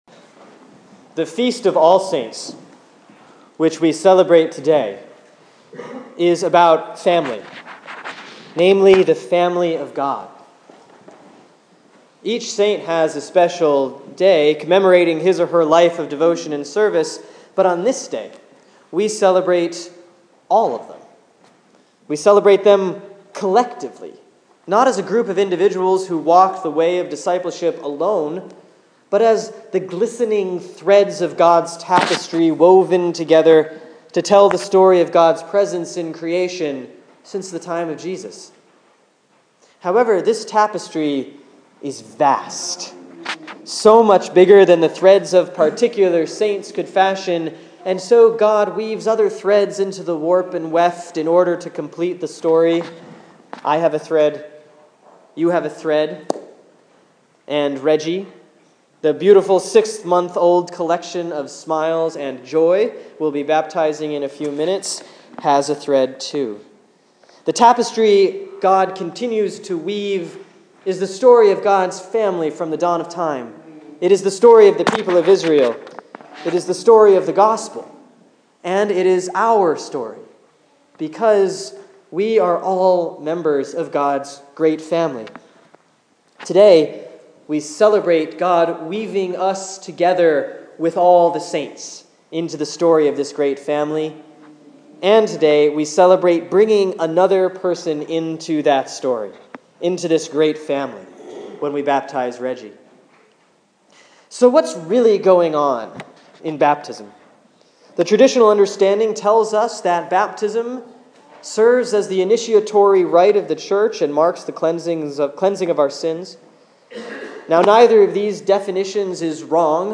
Sermon for Sunday, November 2, 2014 || All Saints Year A